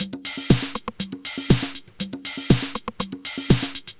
Beat 04 No Kick (120BPM).wav